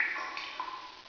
drip1.wav